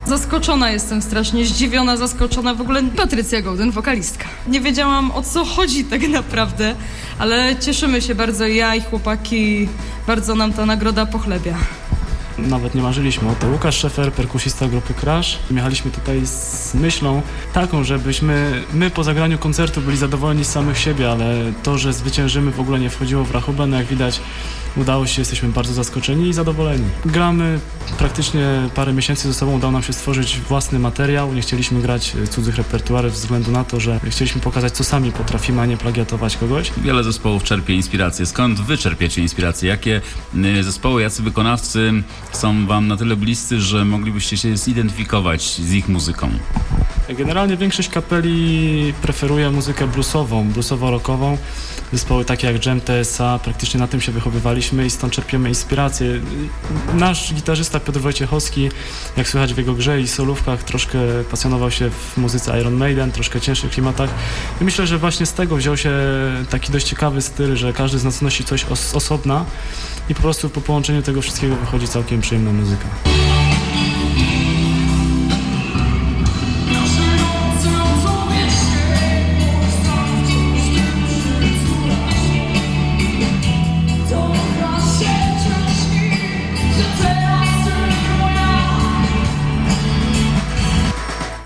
1 maja 2008, MOK w Lesznie